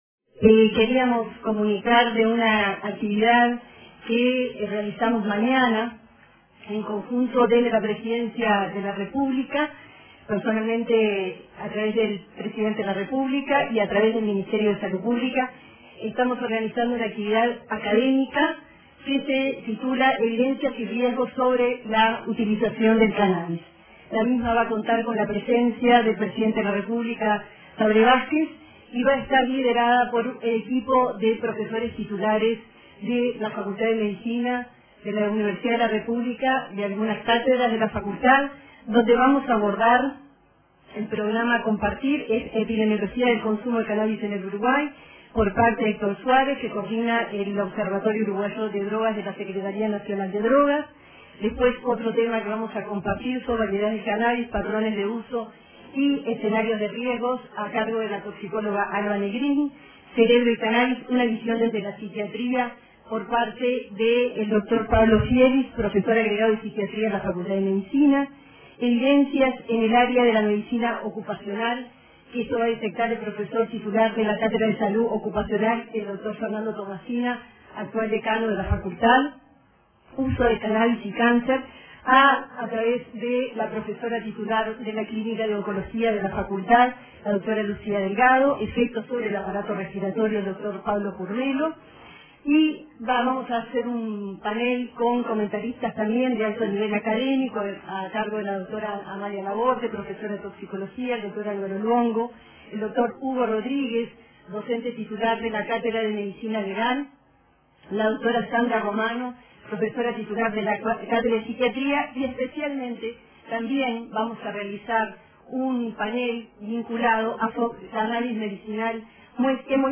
La subsecretaria de Salud Pública, Cristina Lustemberg, informó sobre la actividad académica que se realizará mañana en Torre Ejecutiva con la presencia del presidente Vázquez, relacionada con el estudio del cannabis en distintas disciplinas. Se abordará la epidemiología del consumo del cannabis en Uruguay, las variedades, los patrones de uso y escenarios de riesgo y cannabis medicinal, informó tras el Consejo de Ministros.